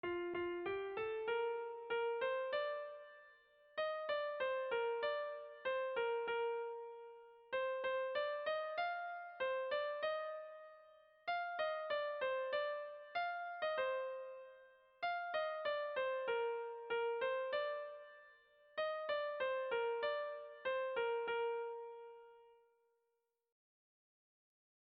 Bertso melodies - View details   To know more about this section
Sei puntuko berdina, 8 silabaz
ABDE